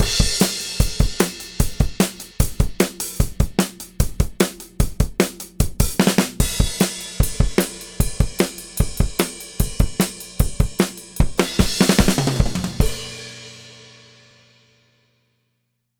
今回はDuet 3 を iPhoneにつなぎ、リハーサルスタジオでドラムレコーディングを試してみました。
• LEWITT LCT 040 MATCH STEREO PAIR
iPhone の DAW は GarageBand を使用しました。
人力マルチ録音
クリック音を聞きながら各パートを個別に叩いています。録音はちょっと大変ですが、楽器のカブリ音が全く入らないためクリアなサウンドが得られ、ドラム音源的に後から編集もできるので便利です。